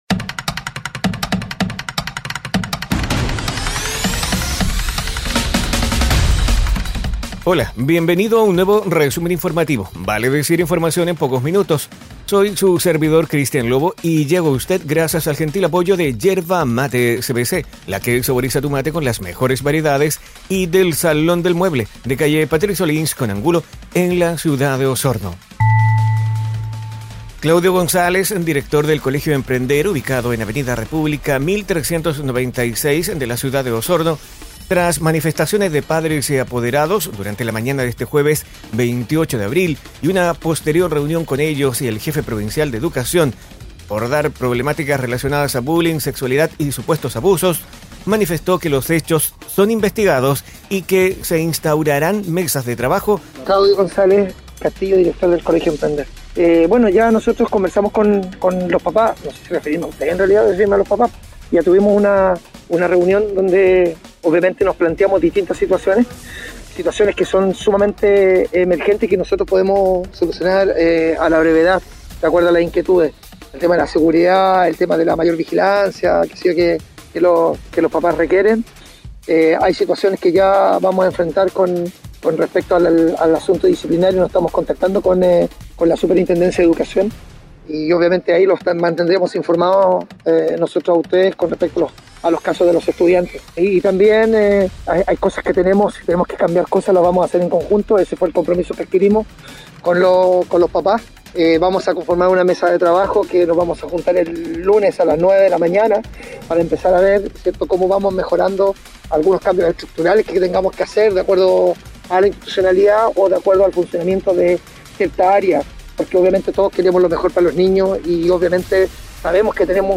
Resumen Informativo ▶ Podcast 29 de abril de 2022